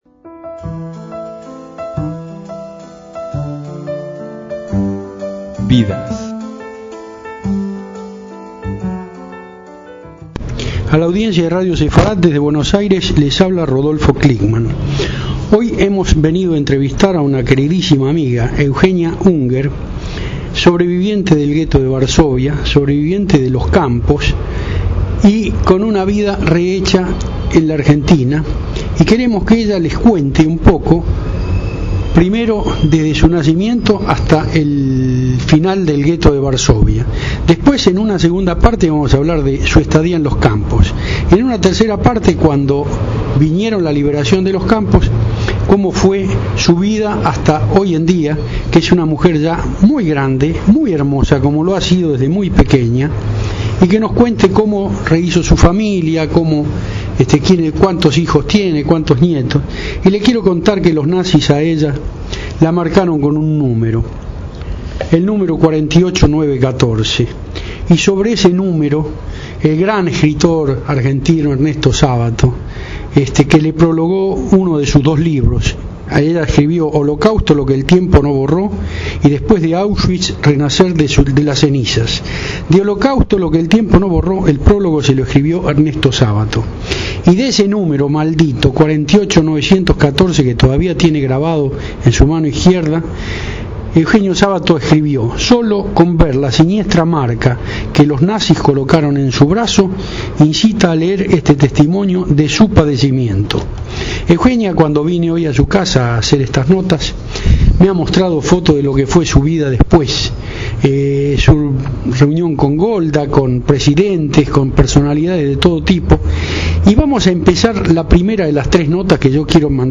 HOMENAJE